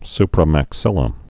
(sprə-măk-sĭlə)